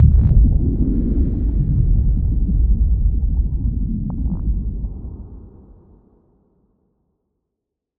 Low End 17.wav